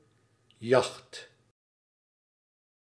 Ääntäminen
IPA : /jɒt/